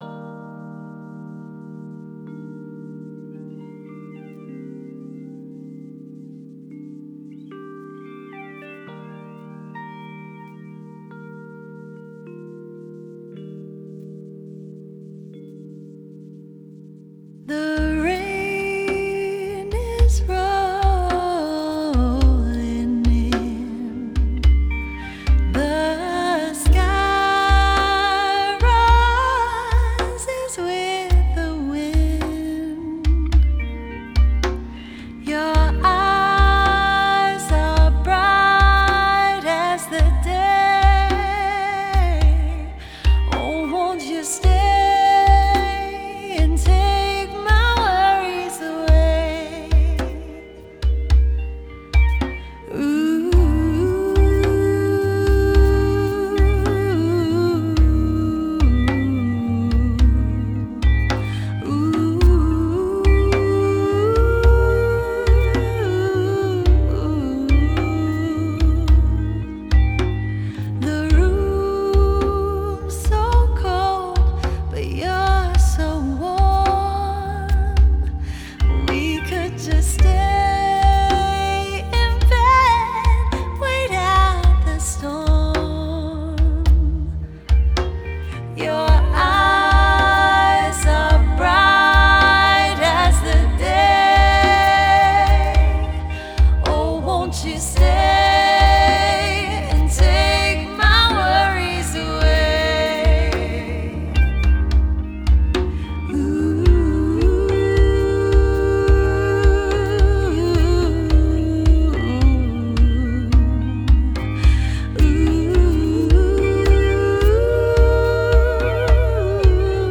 Genre: Folk Pop, Jazzy Folk